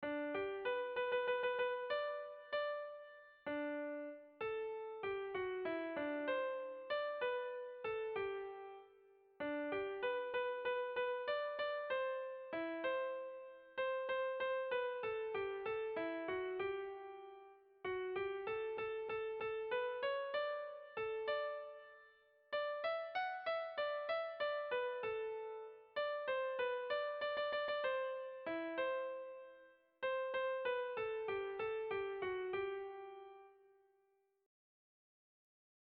Erromantzea
Irun < Bidasoaldea < Gipuzkoa < Euskal Herria
Zortziko handia (hg) / Lau puntuko handia (ip)
AB1DB2